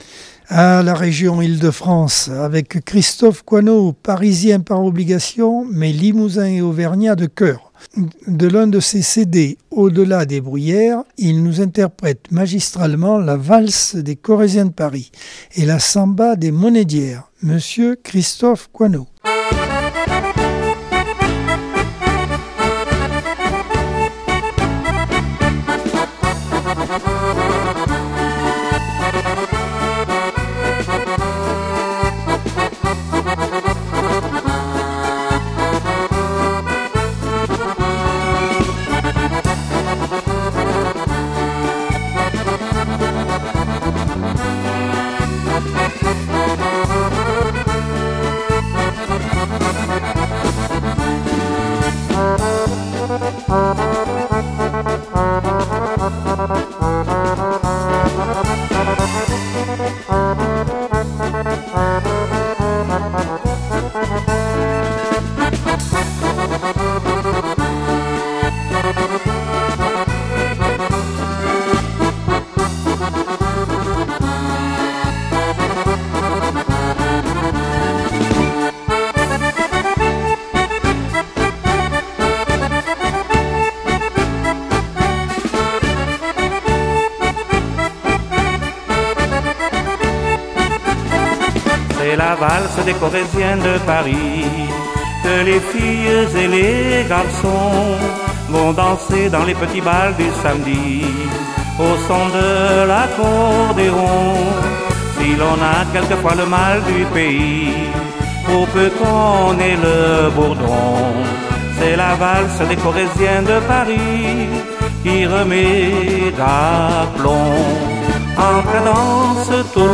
Accordeon 2024 sem 46 bloc 4 - Radio ACX